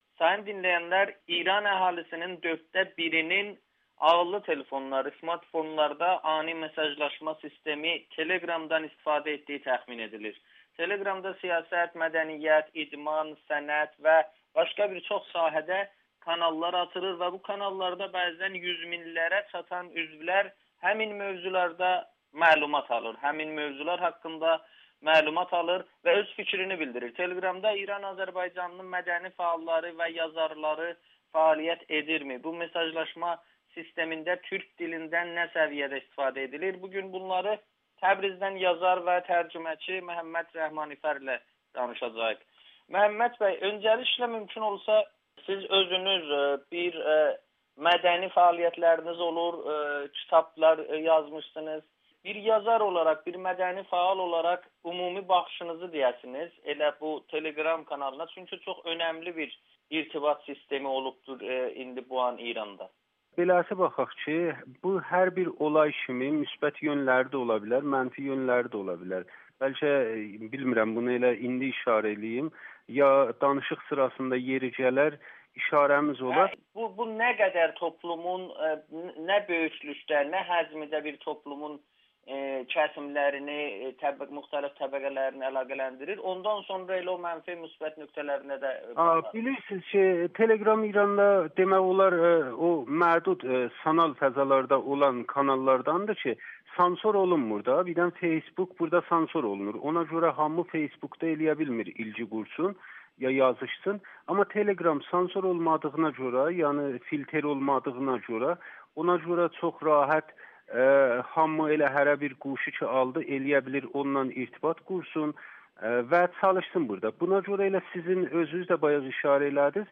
‘Telegram’-ın gənc istifadəçiləri türkcə yazır [Audio-Müsahibə]